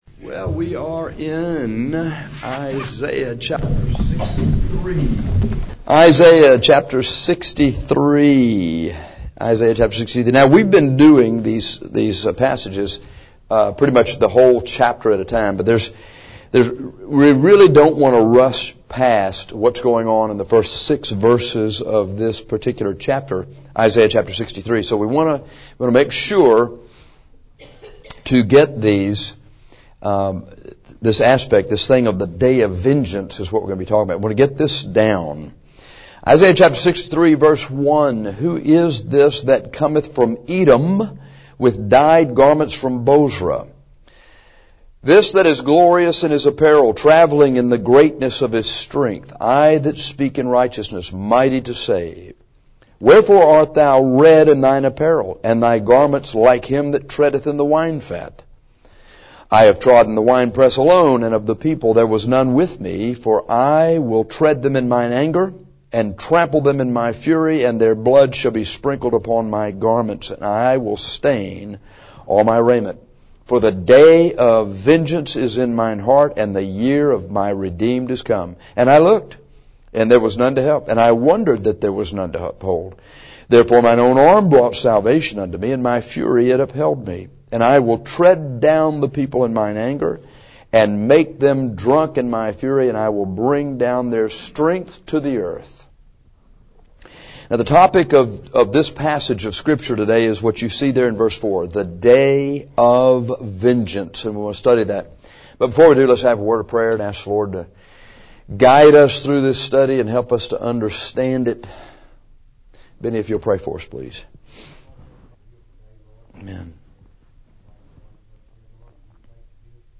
This lesson deals with some important aspects of this future date on God’s calendar.